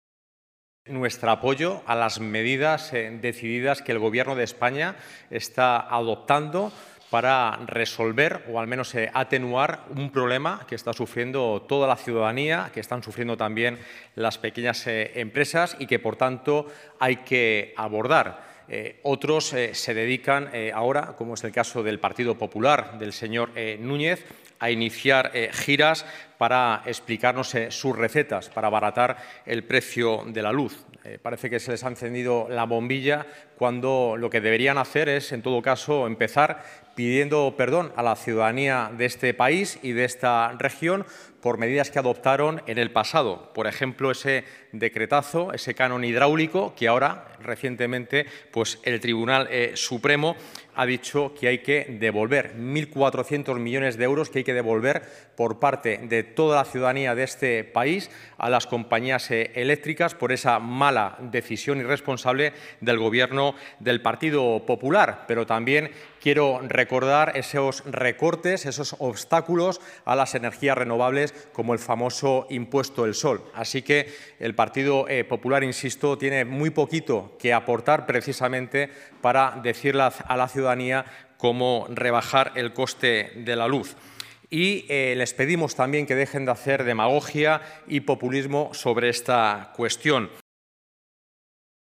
El consejero de Desarrollo Sostenible, José Luis Escudero, ha mostrado en rueda de prensa el apoyo del Gobierno de Castilla- La Mancha a las medidas adoptadas por Gobierno de España respecto al abaratamiento de la luz